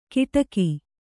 ♪ kiṭaki